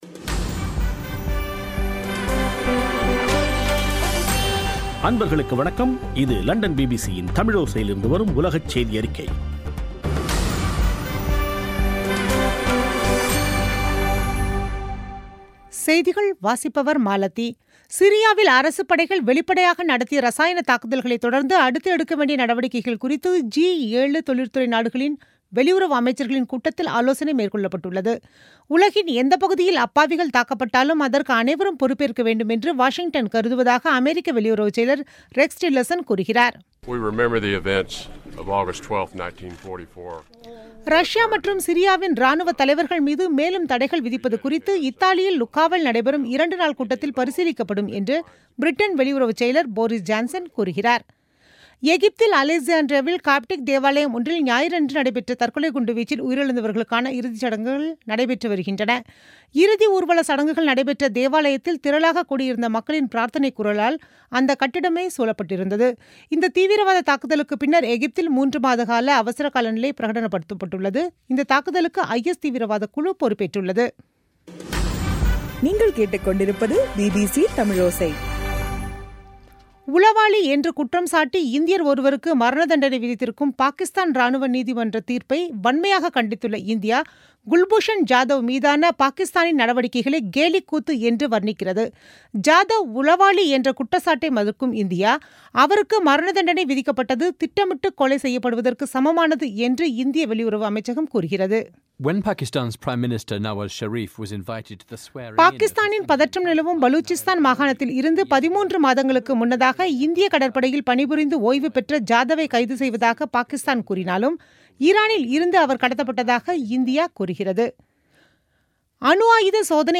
பிபிசி தமிழோசை செய்தியறிக்கை (10/04/2017)